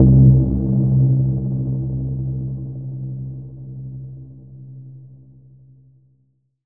Norstrom Bass.wav